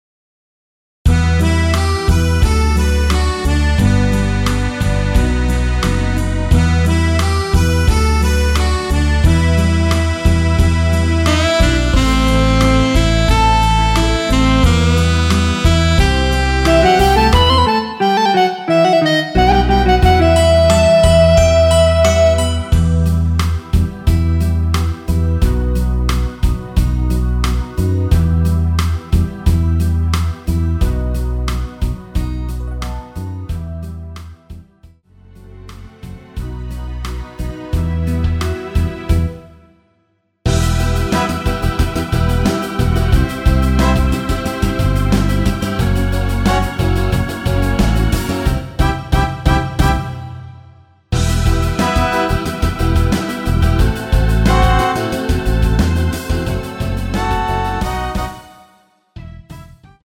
원키에서(-1)내린 MR입니다.
Em
앞부분30초, 뒷부분30초씩 편집해서 올려 드리고 있습니다.